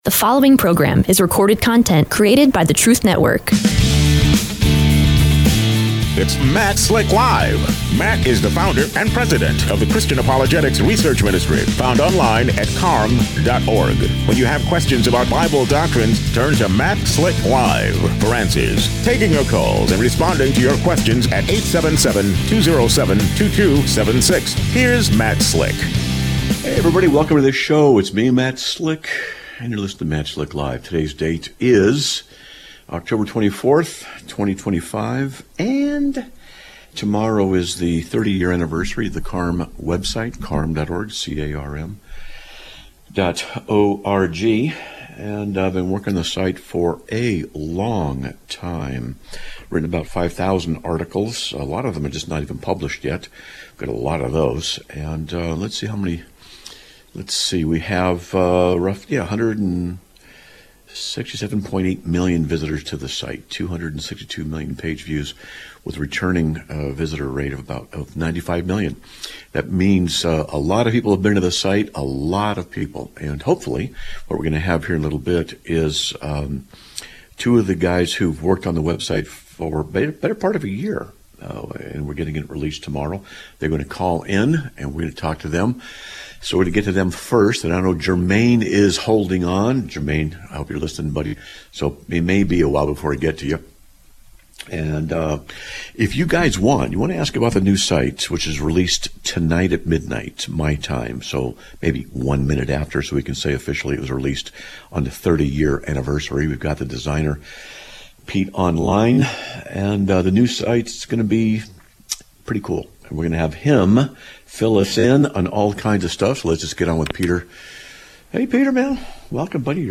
Live Broadcast of 10/24/2025) is a production of the Christian Apologetics Research Ministry